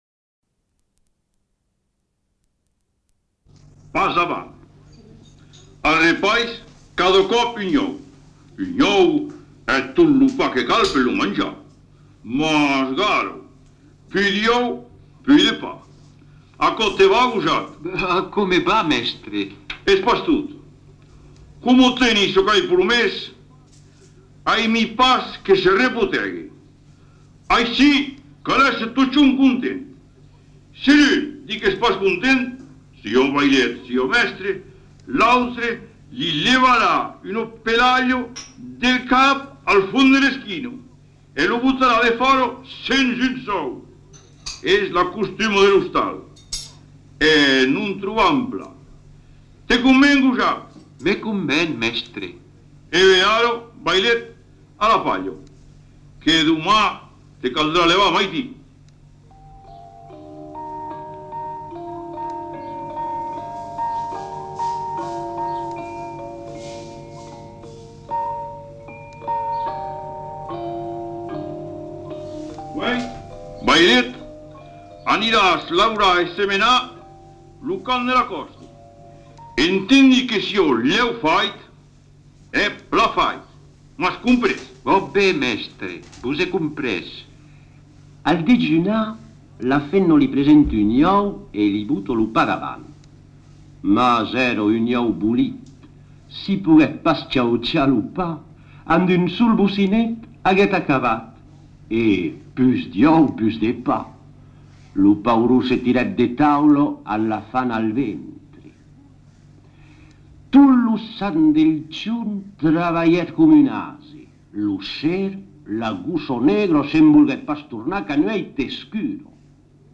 Genre : conte-légende-récit
Type de voix : voix d'homme Production du son : lu Langue : occitan (languedocien)